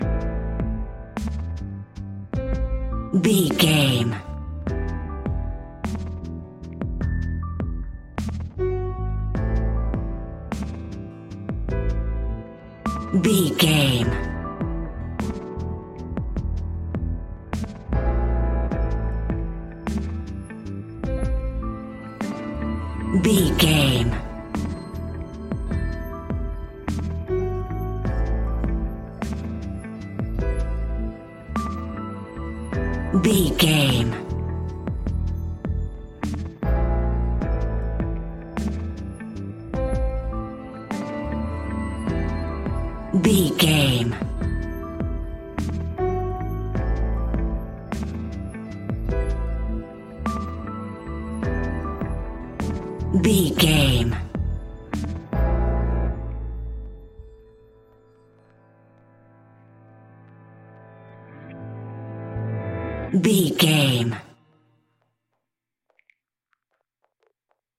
Fast paced
Uplifting
Ionian/Major
A♭
hip hop